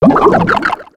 Cri de Méios dans Pokémon X et Y.